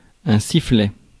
Ääntäminen
Synonyymit chant sifflement Ääntäminen France: IPA: [si.flɛ] Haettu sana löytyi näillä lähdekielillä: ranska Käännös Ääninäyte Substantiivit 1. whistle UK US Suku: m .